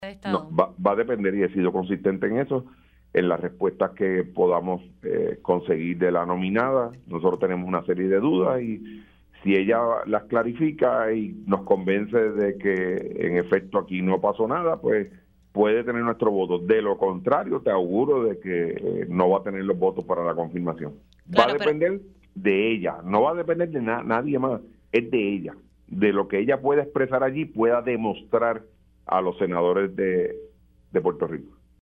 208-JUAN-OSCAR-MORALES-SENADOR-PNP-COFNIRMACION-DE-FERRAIOULI-DEPENDERA-DE-SUS-RESPUESTAS.mp3